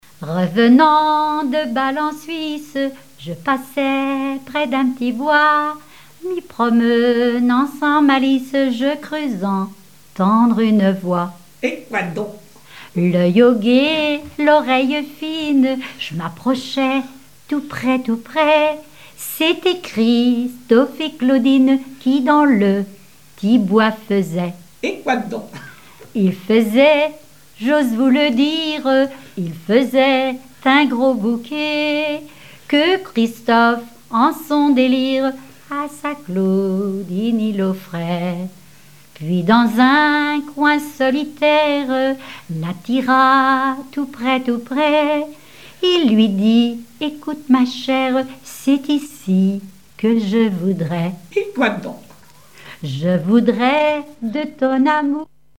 Genre strophique
chansons populaires et traditionnelles
Pièce musicale inédite